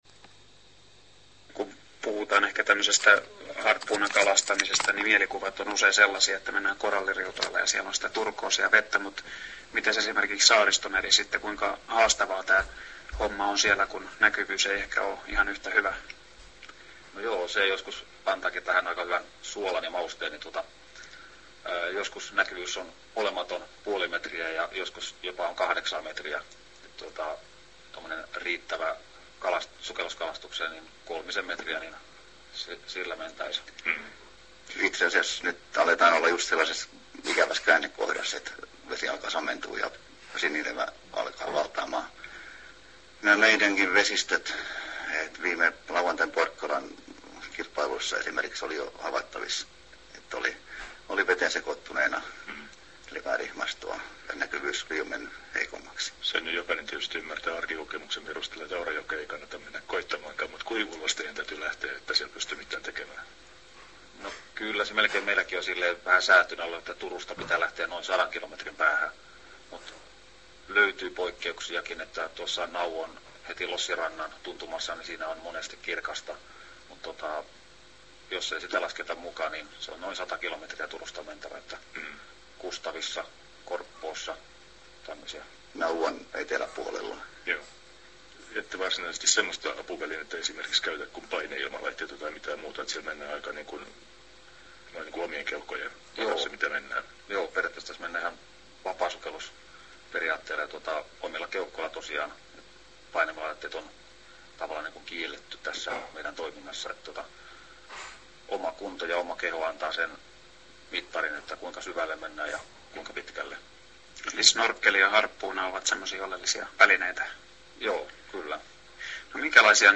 (2,63 Mt,  .mp3.  5,44min  kooste lähetyksestä)